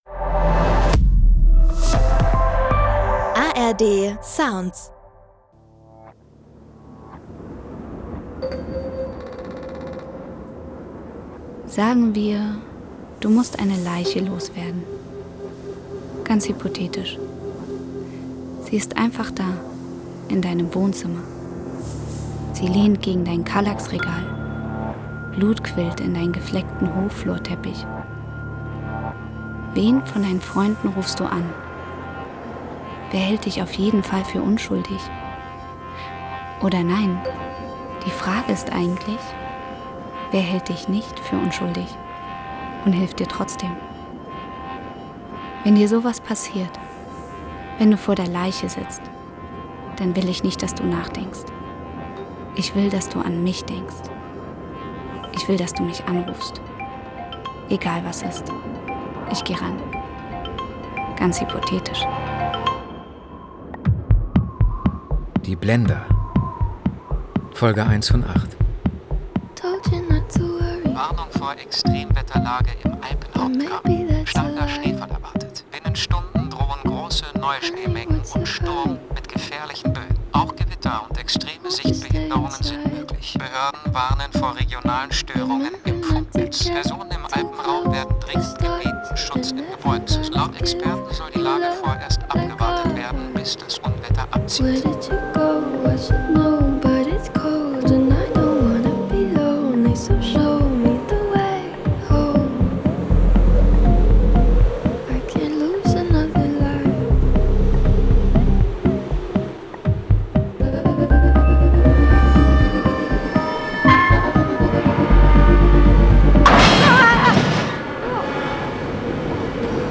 Die Blender: Alma (1/8) – Tod im abgelegenen Chalet im Schnee ~ Die Blender – Crime-Hörspiel-Serie Podcast